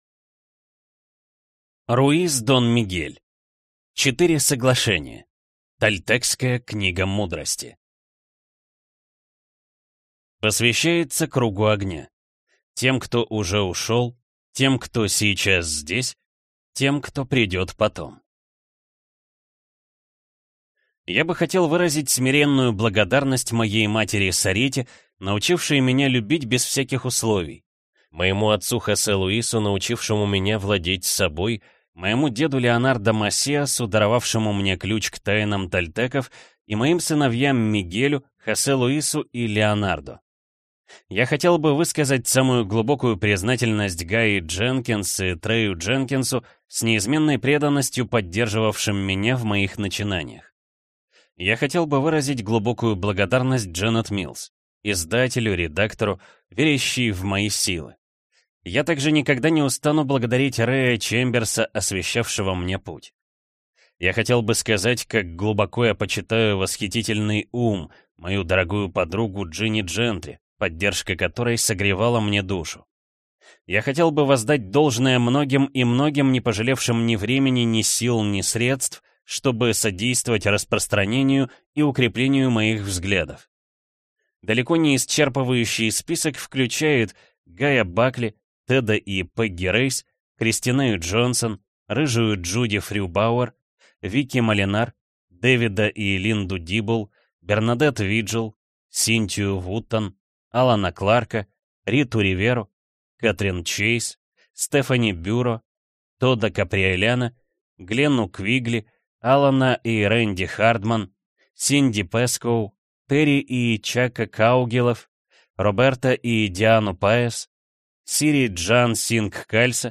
Аудиокнига Четыре соглашения. Тольтекская книга мудрости | Библиотека аудиокниг